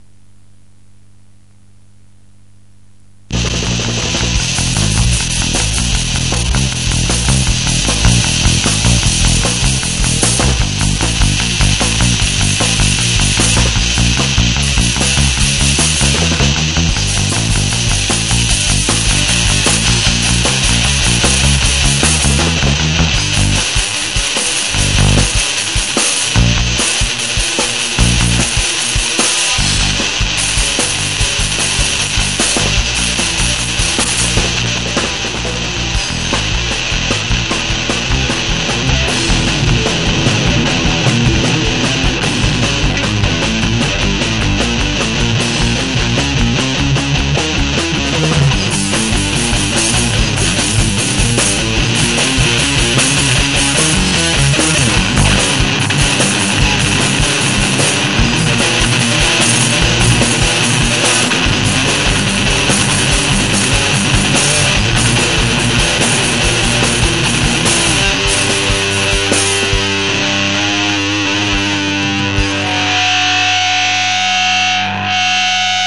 Концерт 17.06.99 в Центре Латышской Культуры в Двинске.